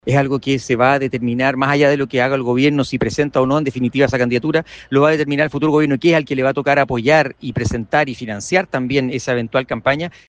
El diputado del Partido Republicano, Stephan Schubert, reprochó la inminente inscripción de la candidatura de la ex mandataria, ya que afirmó, será tarea de José Antonio Kast apoyar o no la carta elegida por el presidente Gabriel Boric.